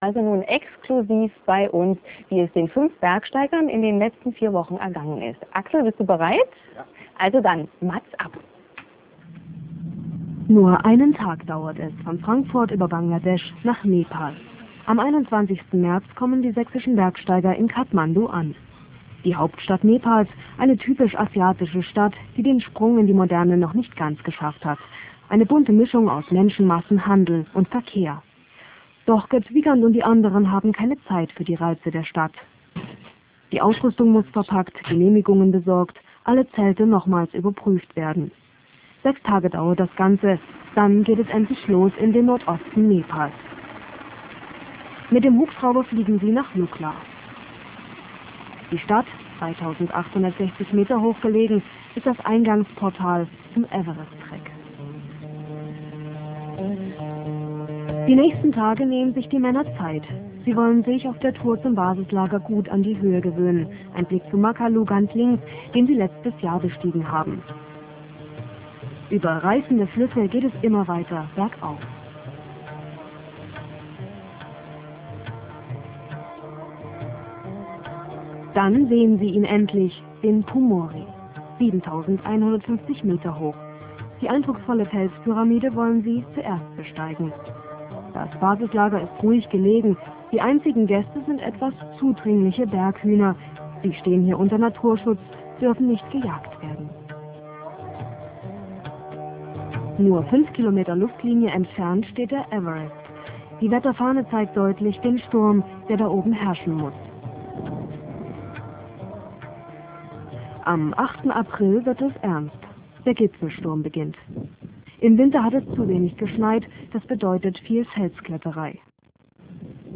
Zweites Telefonat via Satellitentelefon ins